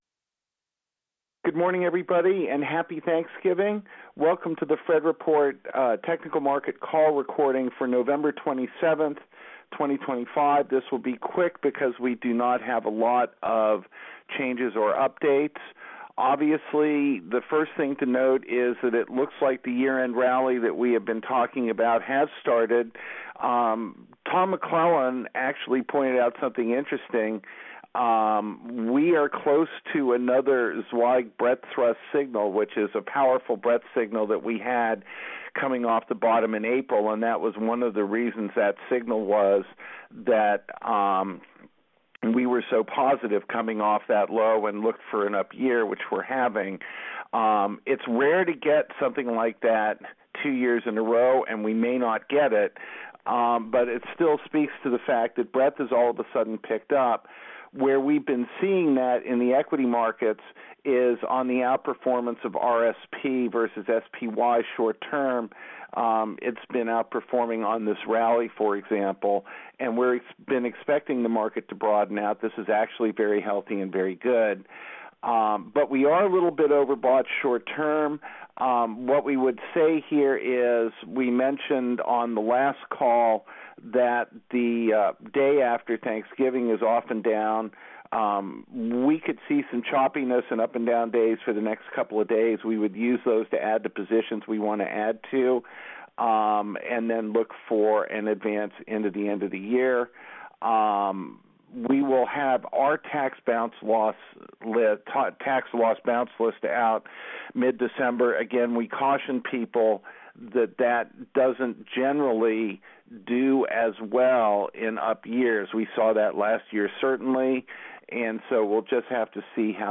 Conference Call Recording: